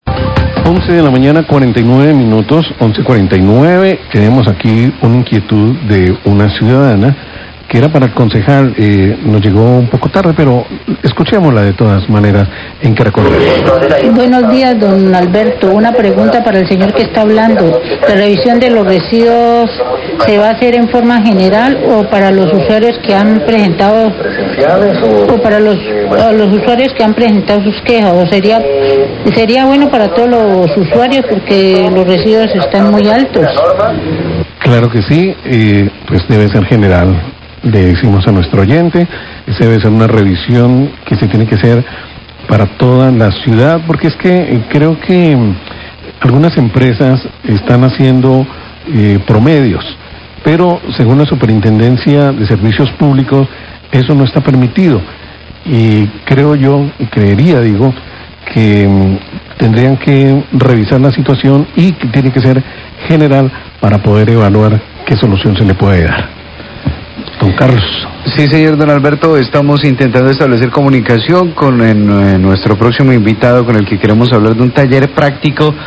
Oyente pregunta si revisión de cobros servicios públicos será general o por reclamo
Radio